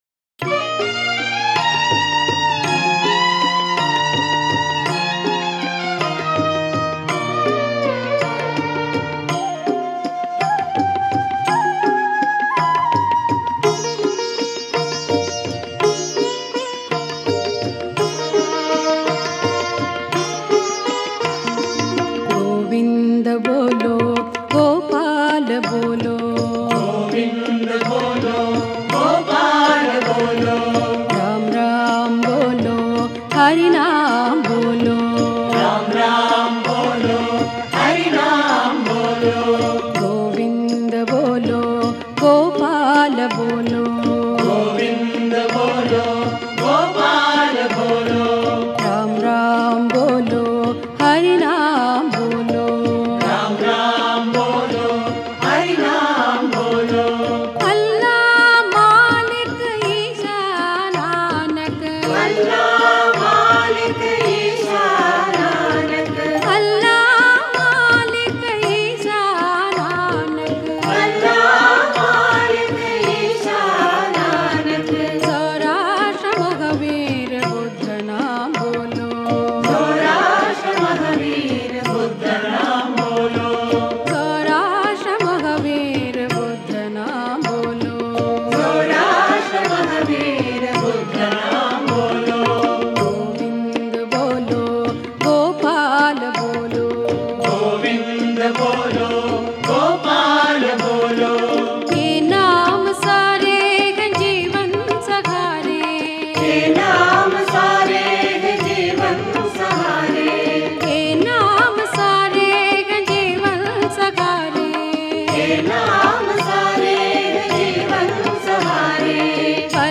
Author adminPosted on Categories Sarva Dharma Bhajans